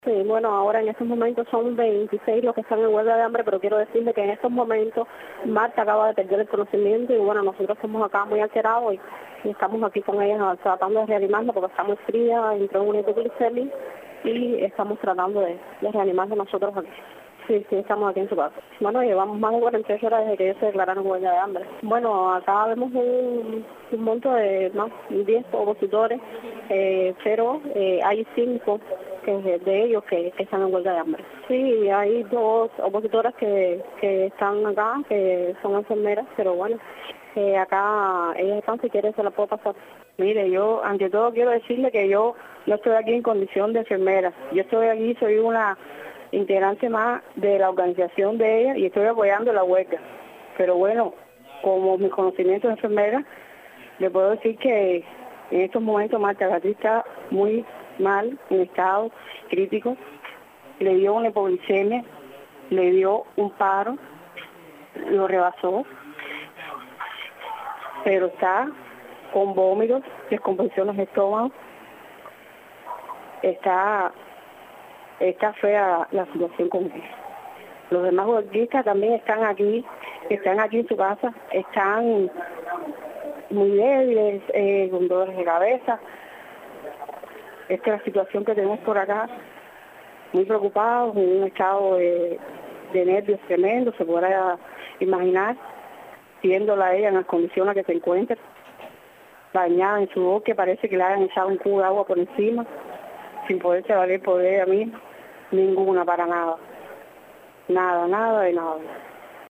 Declaraciones de las opositoras